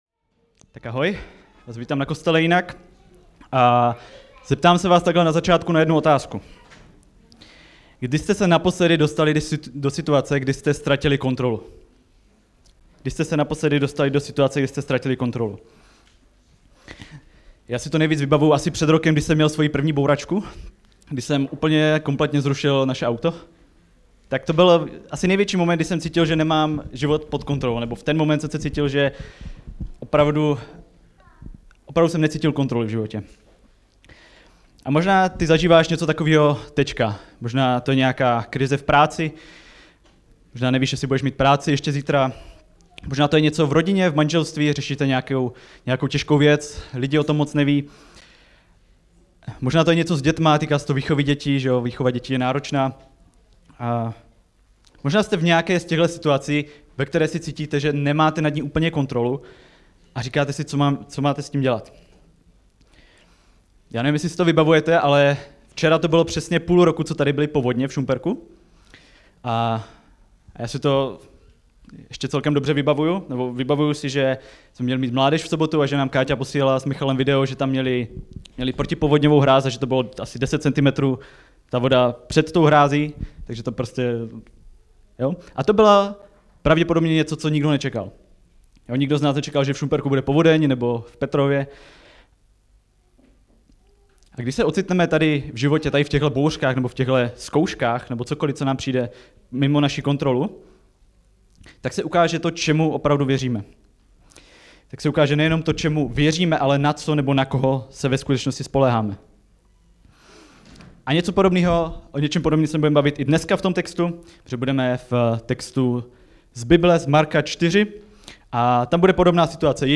Strach Samostatná kázání Marek Sleduj Poslechni Ulož